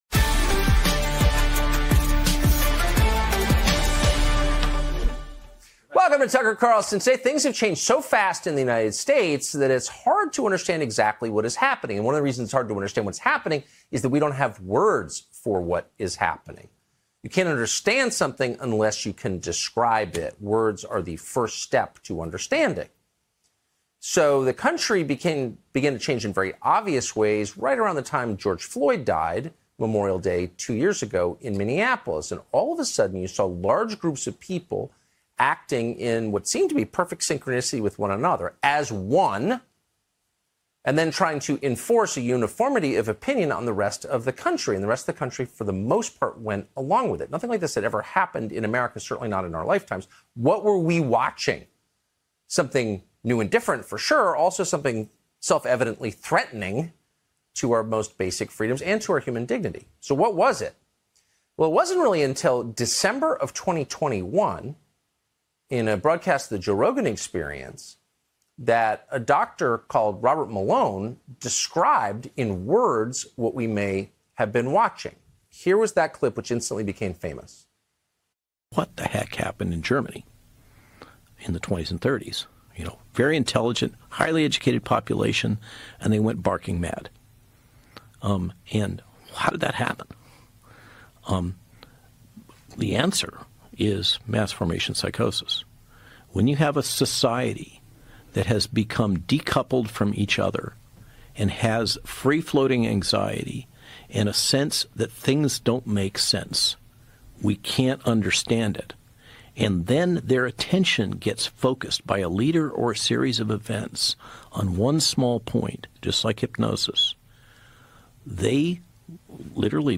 טאקר קרלסון מארח את פרופ' מטיאס דסמט.